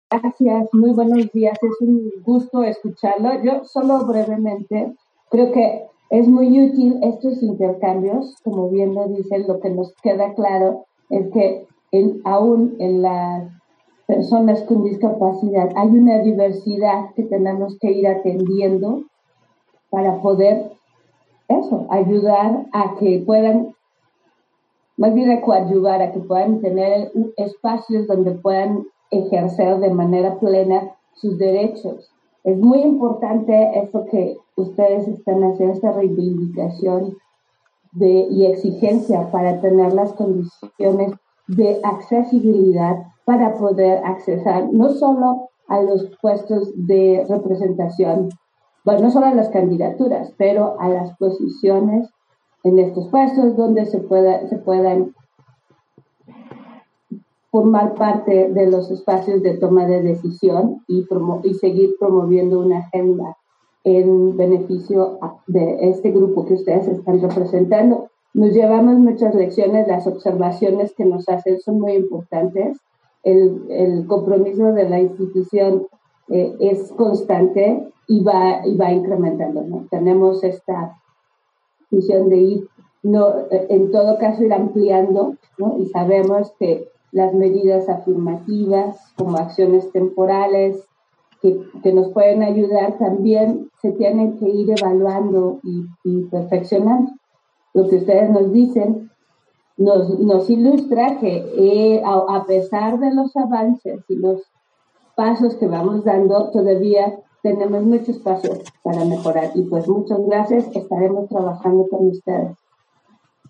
Intervenciones de Consejeras y Consejeros del INE, en la reunión